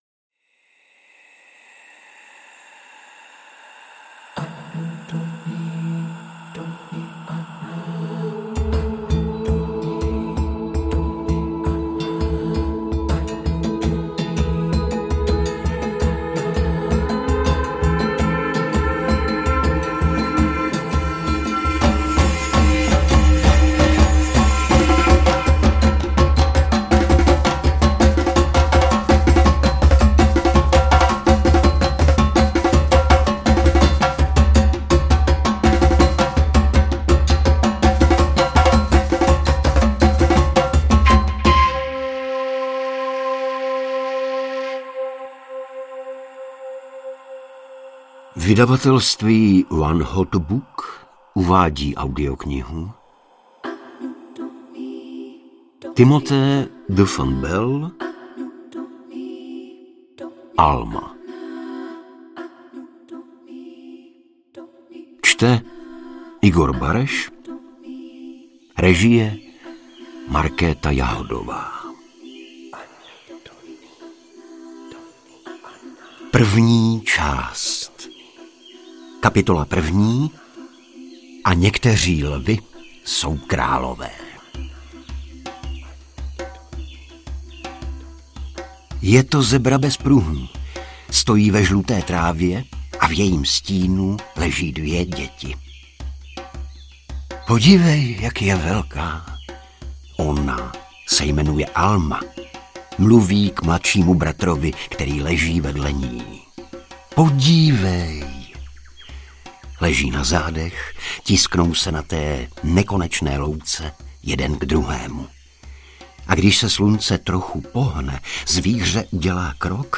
Interpret:  Igor Bareš
AudioKniha ke stažení, 49 x mp3, délka 12 hod. 13 min., velikost 675,4 MB, česky